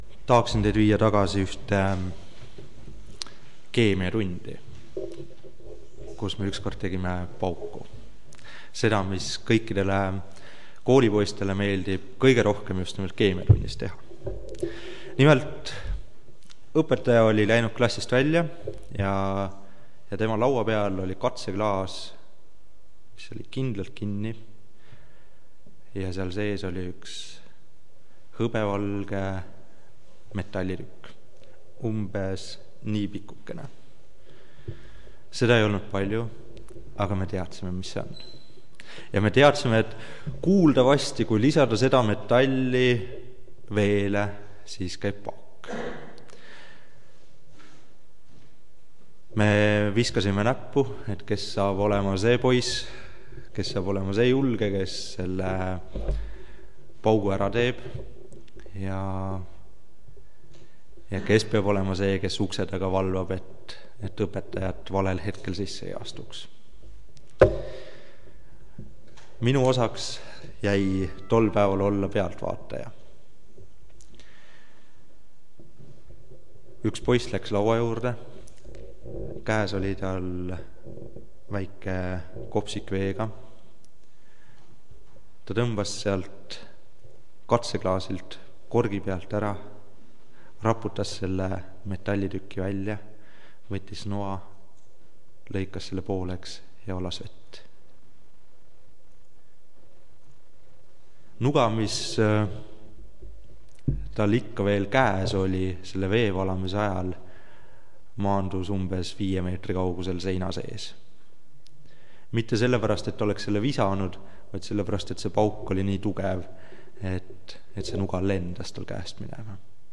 Jutluse teemaks on S O O L